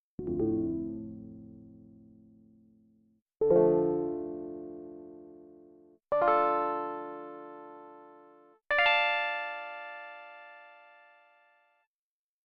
But it uses sync 1→2 to provide the harmonics and adjusts the pitch of 2 with envs to control the amount.
Here’s my quick tines-like using that method:
TINES.afsnd (646 Bytes)
A bit banjo-y in the mid range.